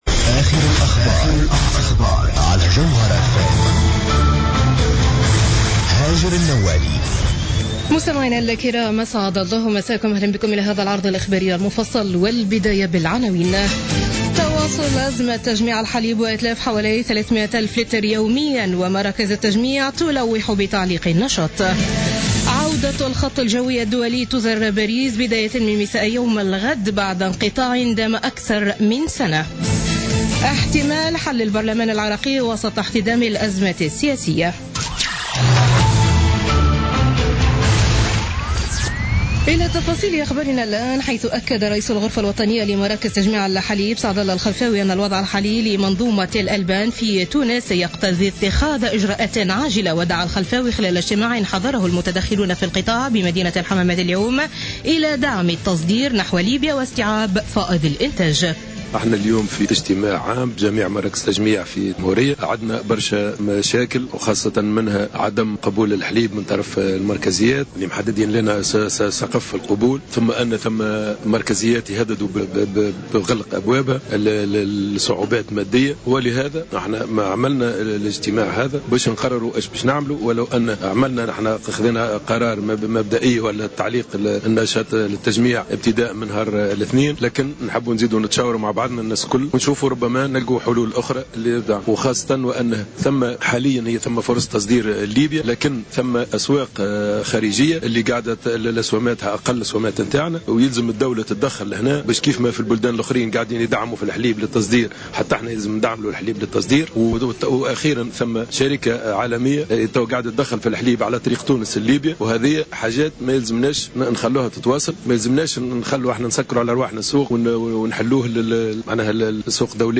Journal Info 19h00 du mercredi 13 avril 2016